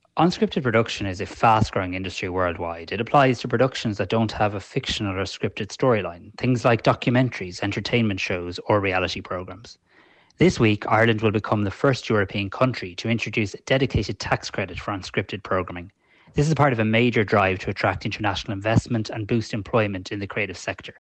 Tanaiste Simon Harris describes the type of shows that’ll be able to avail of it: